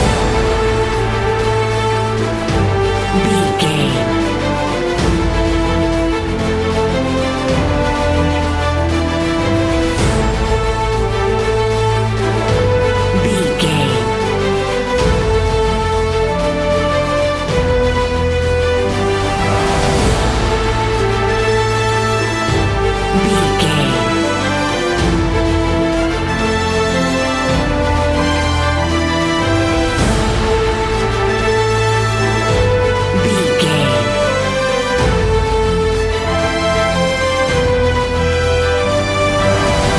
Epic / Action
Uplifting
Aeolian/Minor
energetic
heavy
intense
brass
cello
drums
horns
strings
trumpet
violin
hybrid